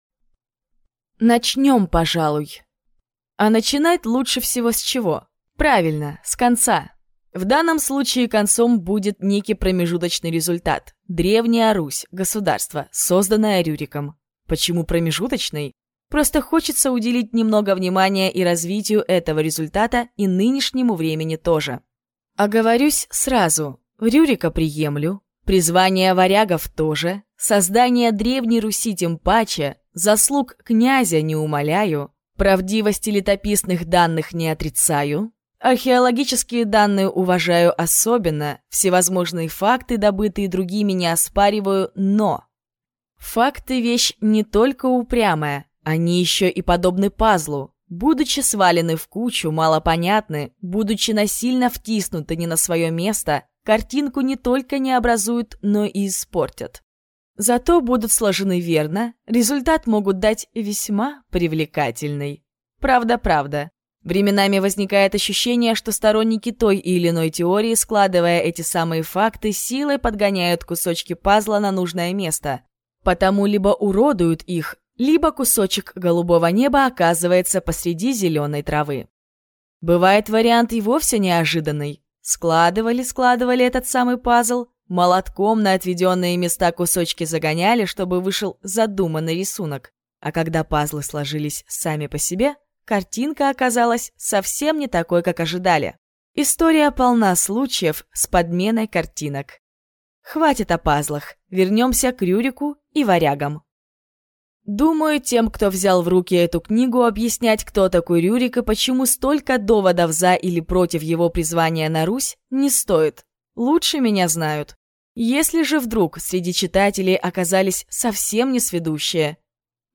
Аудиокнига Ложный Рюрик. О чем молчат историки | Библиотека аудиокниг
Прослушать и бесплатно скачать фрагмент аудиокниги